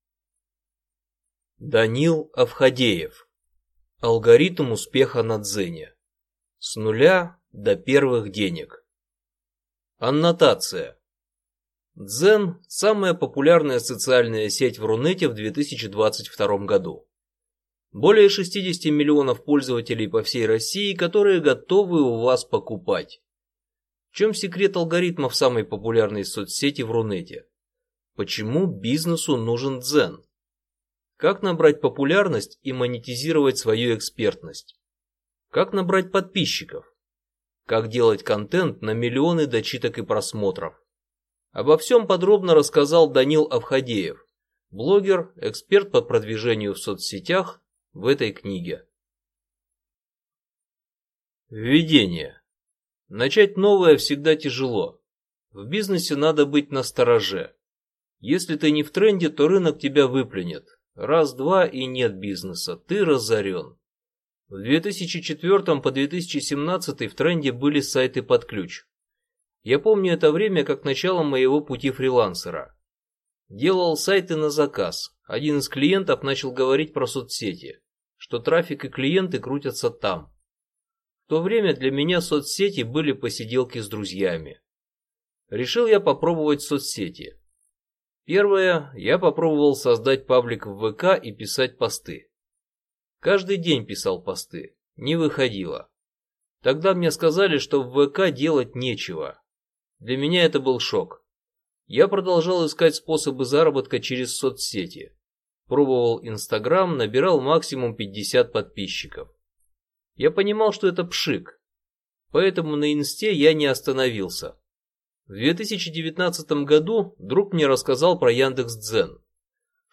Аудиокнига Алгоритм успеха на Дзене. С нуля до первых денег | Библиотека аудиокниг